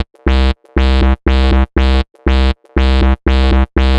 TSNRG2 Off Bass 009.wav